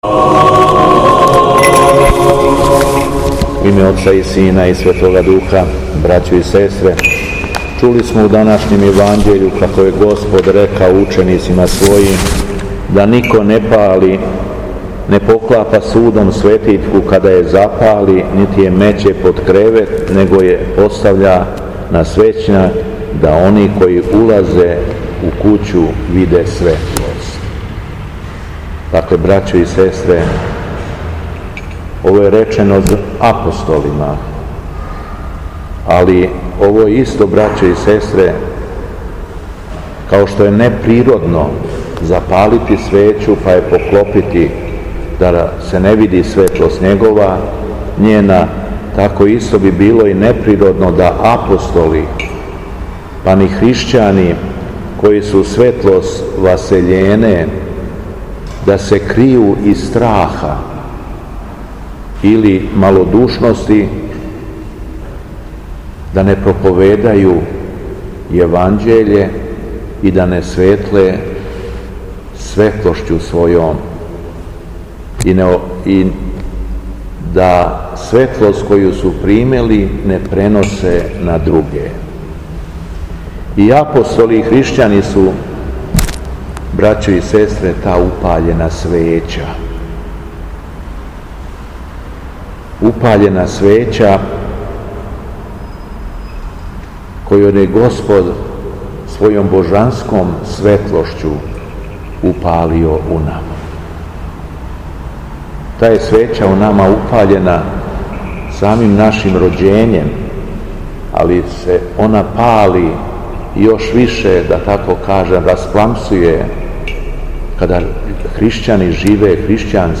У понедељак девети по Духовима, када наша Света Црква прославља успење свете Ане, мајке Пресвете Богородице, Његово Преосвештенство Епископ шумадијски Господин Јован служио је свету архијерејску литургију у храму Светога Саве у крагујевачком насељу Аеродром.
Беседа Његовог Преосвештенства Епископа шумадијског г. Јована